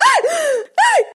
voz nș 0157